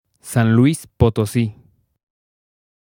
1. ^ Spanish pronunciation: [san ˈlwis potoˈsi]